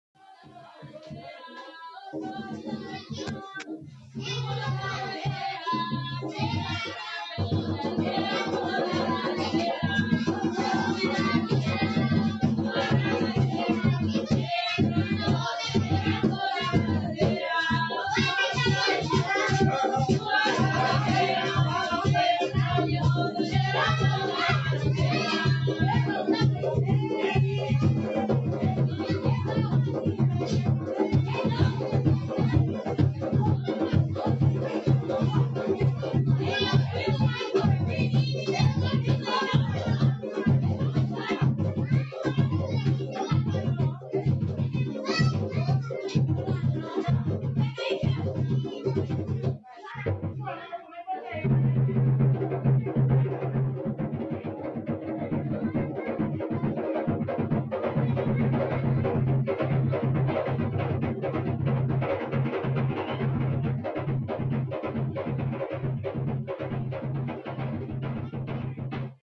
Cantiga abertura do capítulo Caruru de Cosme e Damião.mp3